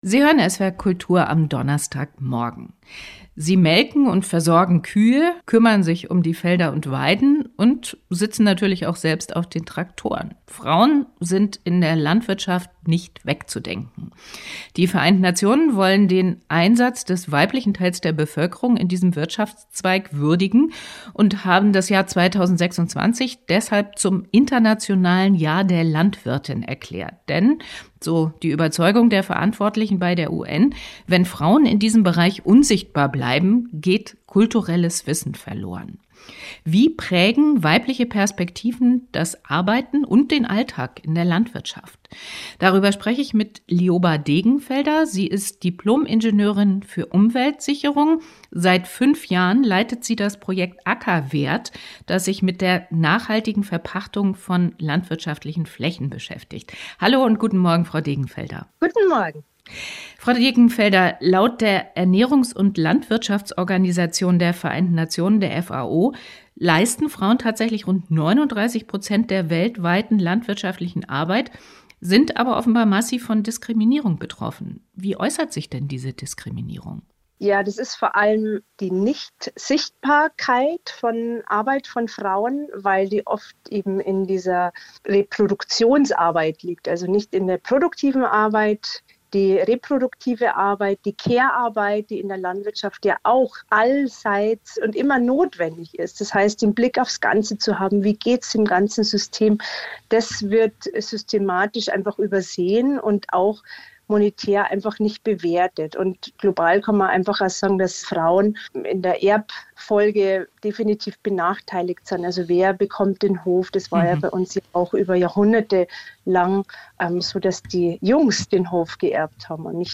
SWR Kultur am Morgen SWR Kultur